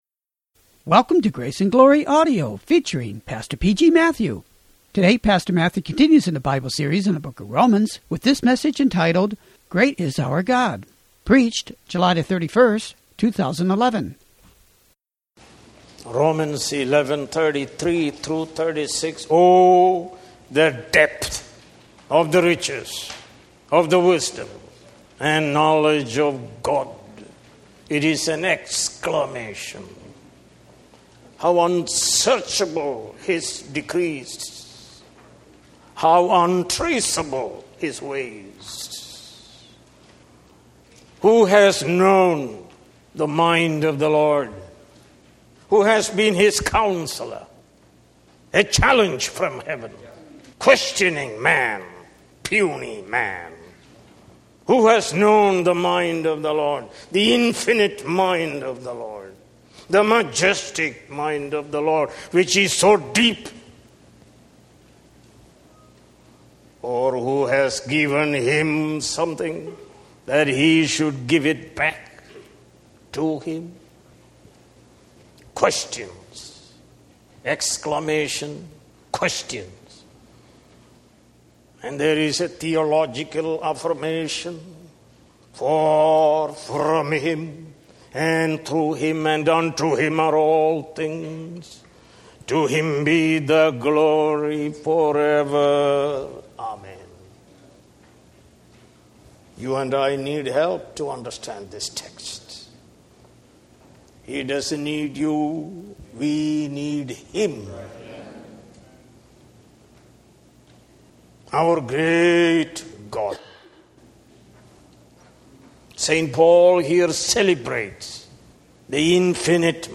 More Sermons From the book of Romans